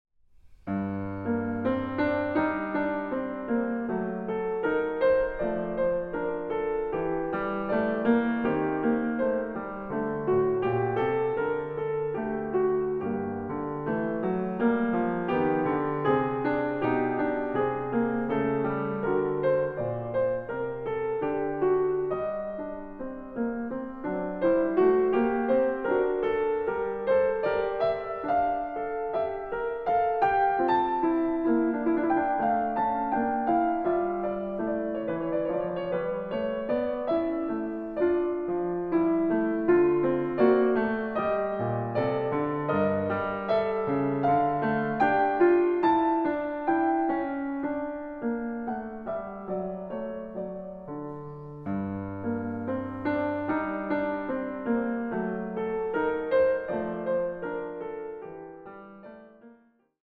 a 1 Clav.